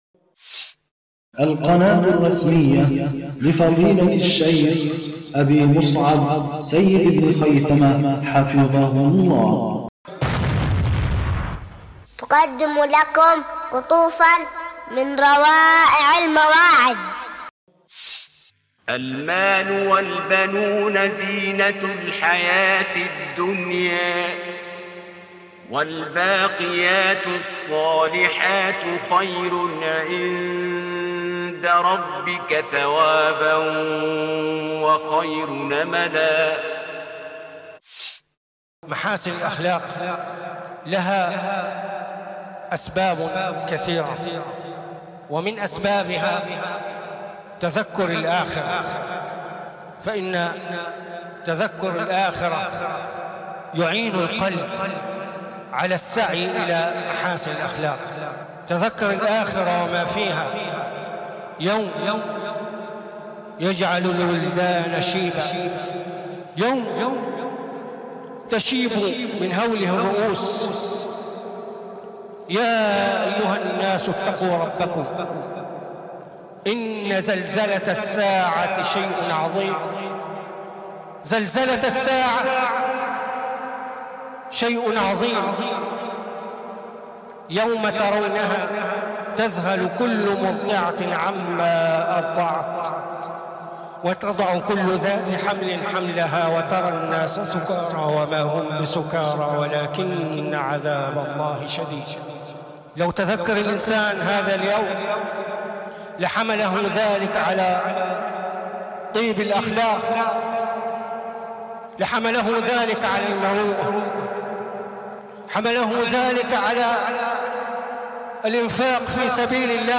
تذكر الآخرة من أهم البواعث على طيب الأخلاق وأعمال البر- من روائع المواعظ